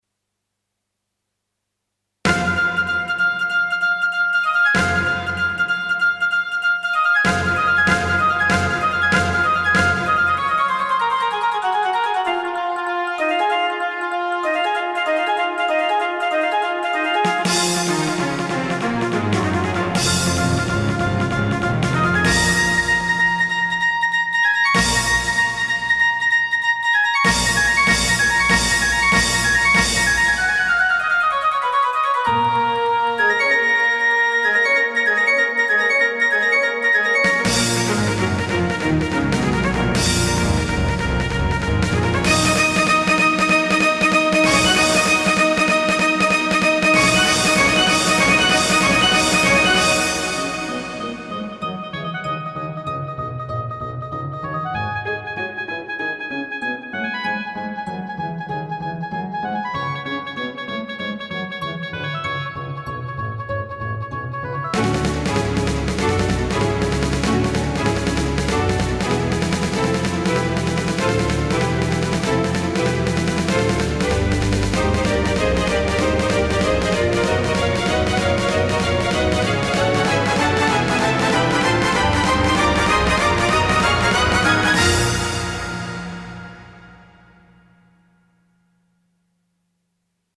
Played by Solo Orchestra